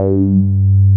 RUBBER G3 P.wav